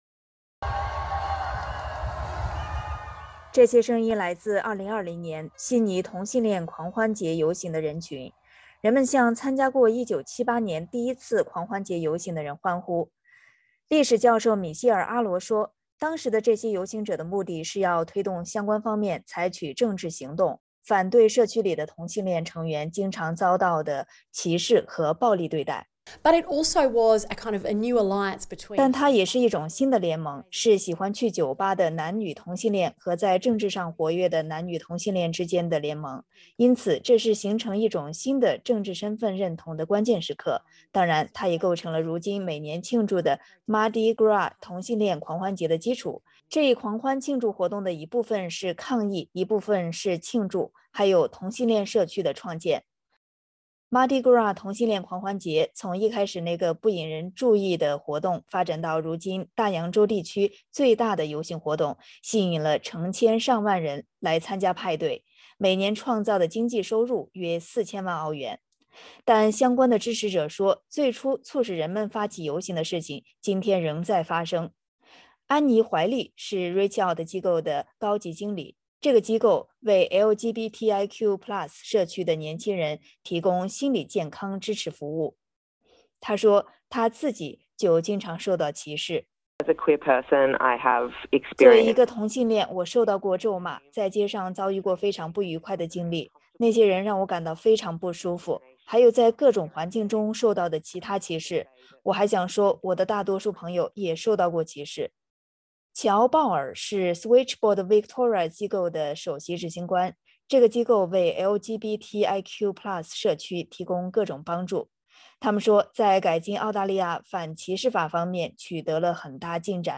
这些声音来自 2020 年悉尼同性恋狂欢节游行的人群，人们向参加过1978 年第一次狂欢节游行的人欢呼。